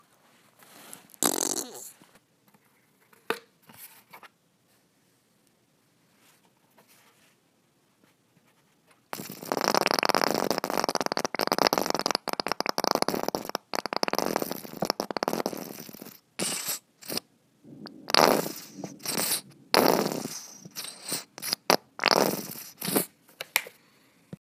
Mustard Squirting / Squeezing / Farts
fart farting farts ketchup mustard Mustard Squirt Squirting sound effect free sound royalty free Memes